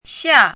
chinese-voice - 汉字语音库
xia4.mp3